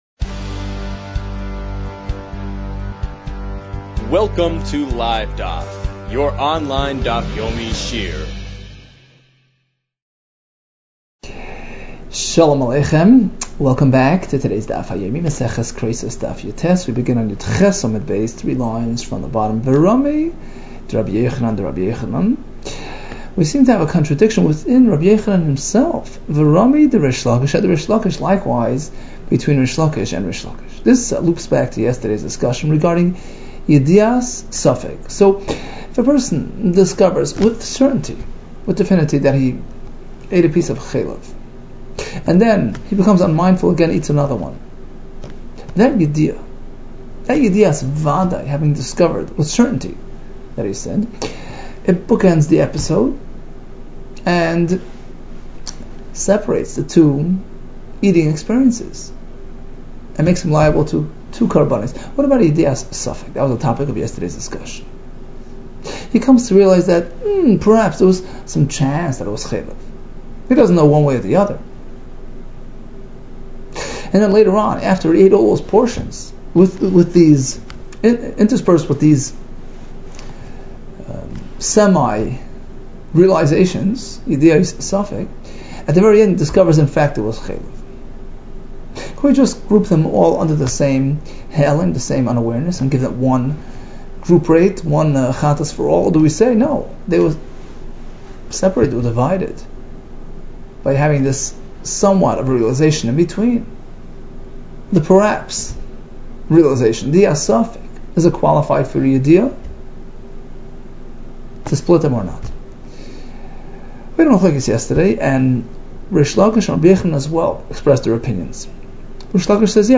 Kereisos 18 - כריתות יח | Daf Yomi Online Shiur | Livedaf